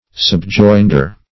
Subjoinder \Sub*join"der\, n.